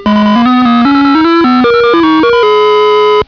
is too groovy
Little ditty number two